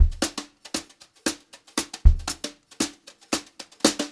116JZBEAT3-L.wav